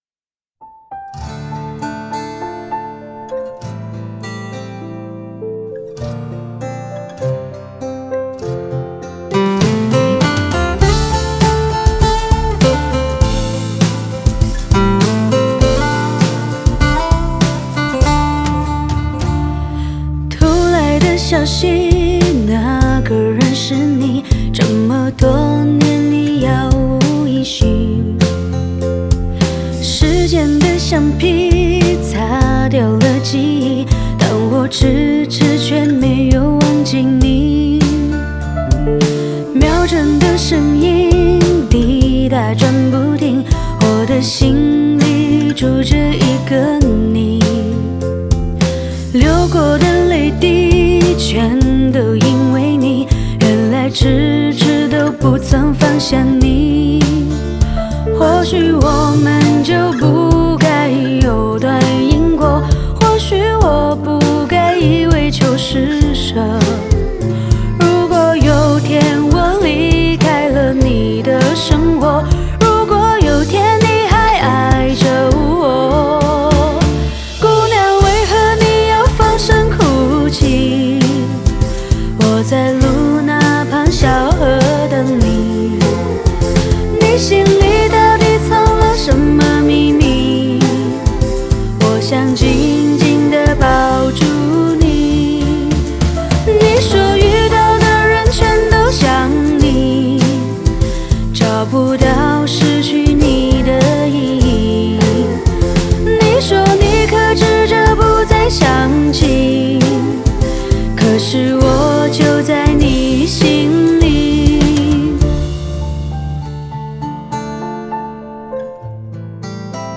吉他
和声